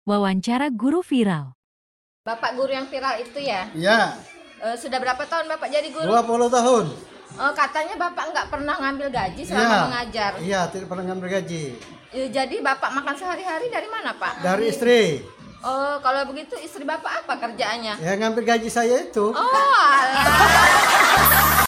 Wawancara Guru VIral Apa Guru sound effects free download